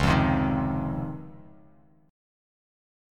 C#M9 chord